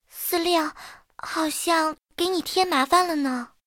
SU-26中破修理语音.OGG